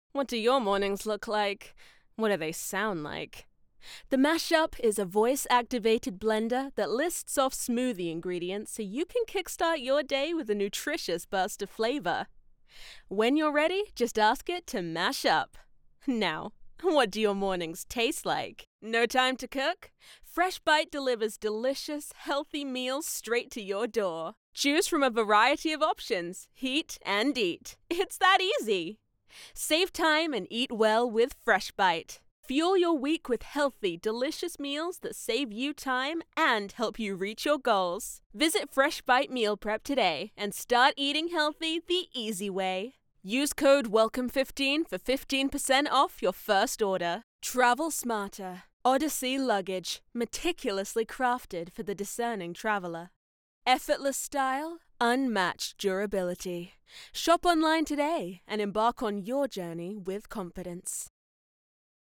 Broadcast-Quality studio, formal training and experience in Animation, Video games and more.
Able to perform in a wide variety of accents and styles. Vocal Range: Androgynous, Female Adult, Female Teen, Male Child / High to Medium-Low Voices.
COMMERCIAL SAMPLE